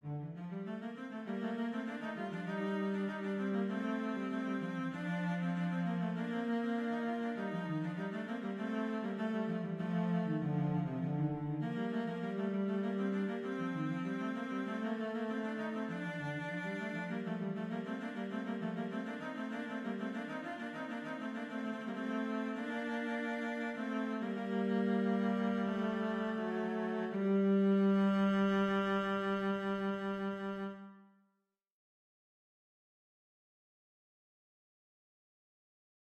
Pour des raisons de clarté auditive, les exemples audios seront ici donnés avec des sons de violoncelle, ceux ci étant préférables aux sons de voix synthétiques.
Mesure 24, la première voix lance ce canon en ré mineur modal (éolien), puis la seconde fait ensuite son entrée dans la même mesure en sol mineur modal également (éolien également). Canon « à la quarte », la première voix débutant sur ré, la seconde sur sol.